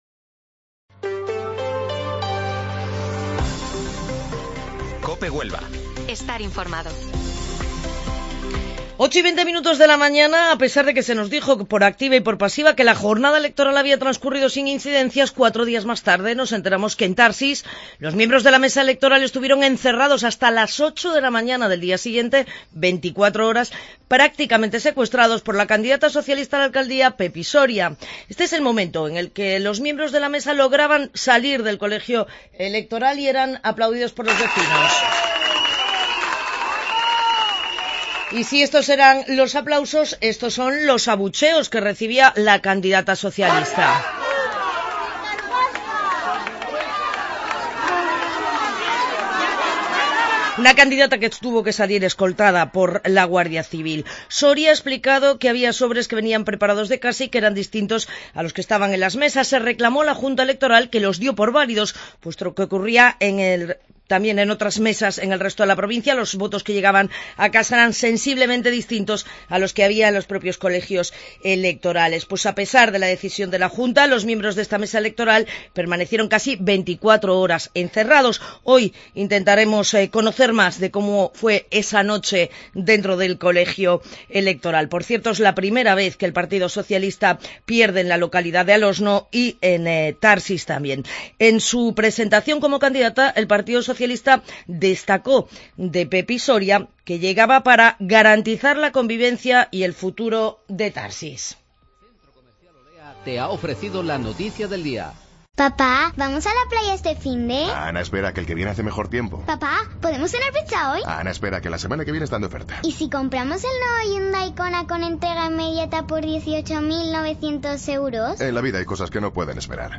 Informativo Matinal Herrera en COPE 1 de Junio